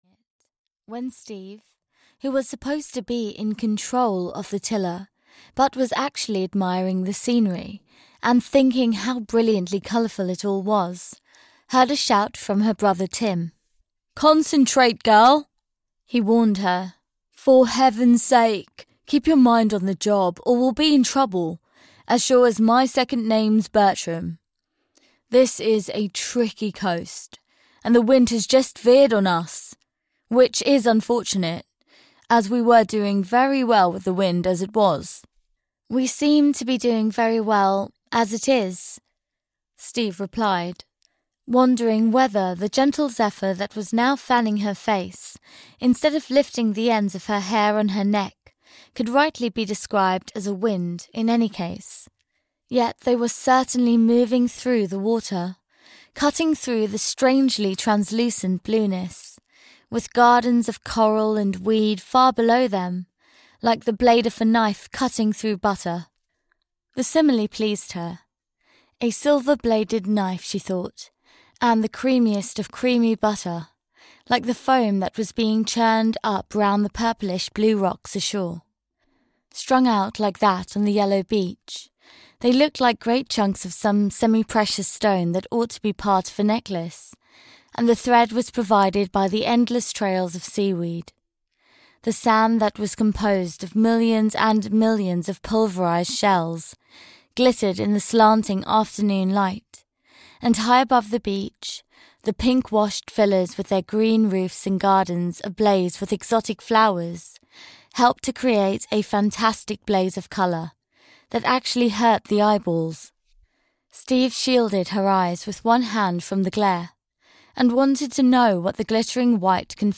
Ukázka z knihy
white-rose-of-love-en-audiokniha